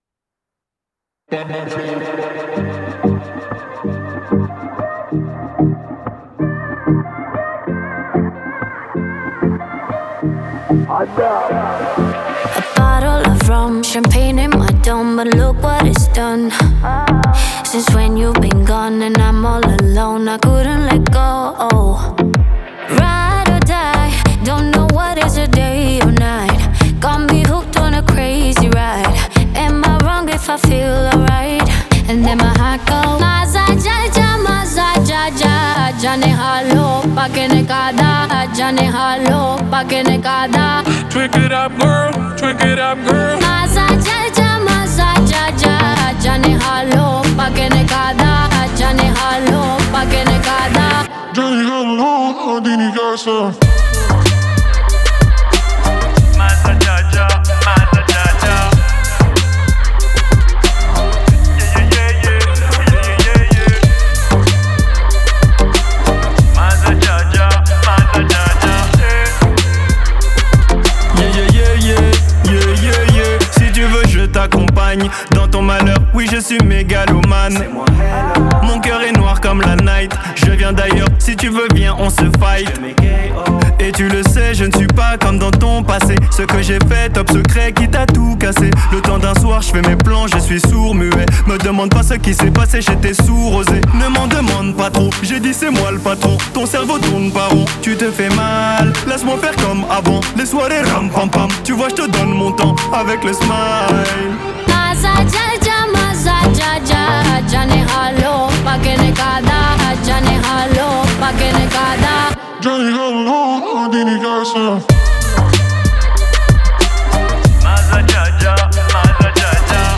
это энергичная поп-песня в стиле дэнс